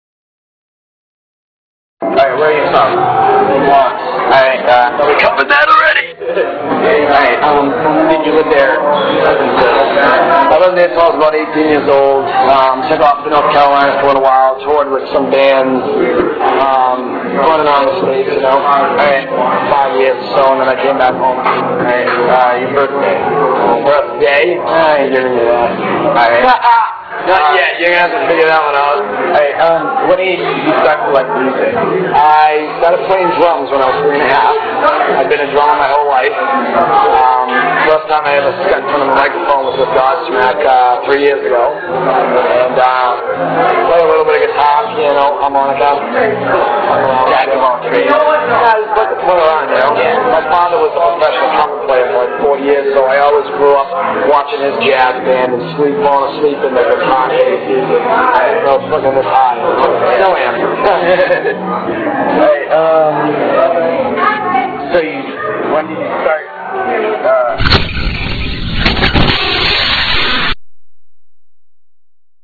interview.ra